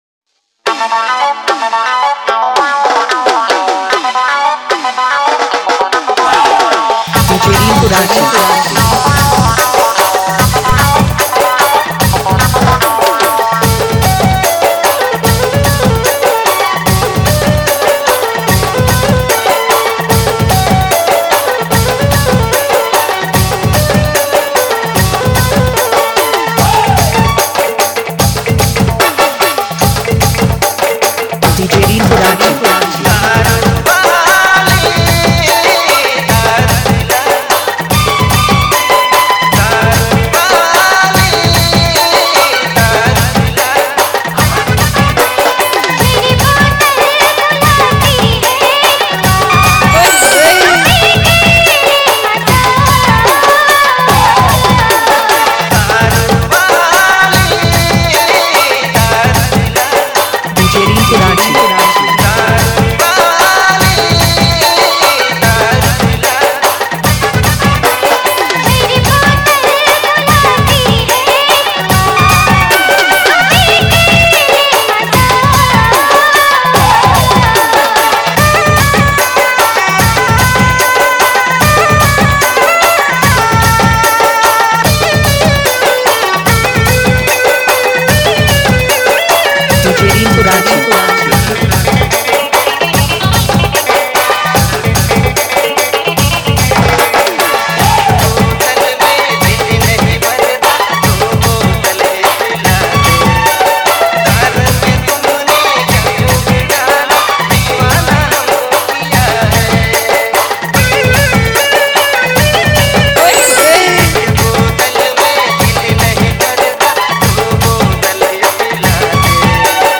vibrant and energetic Nagpuri DJ remix